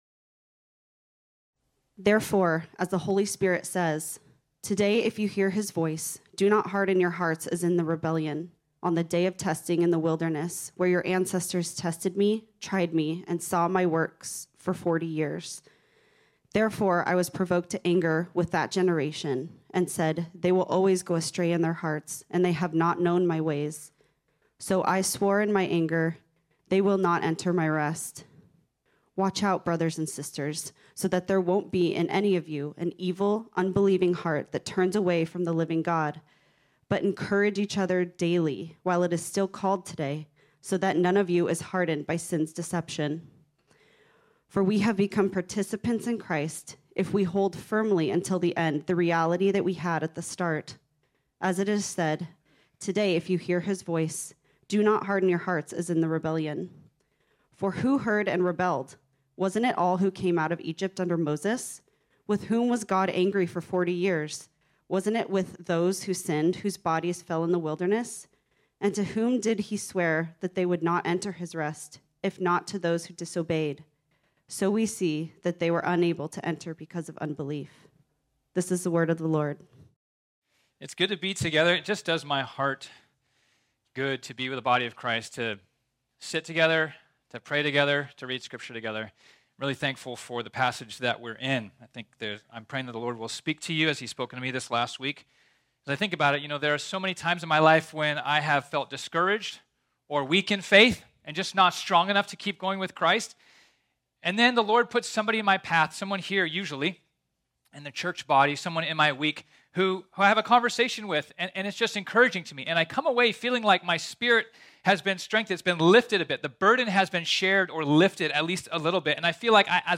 This sermon was originally preached on Sunday, October 16, 2022.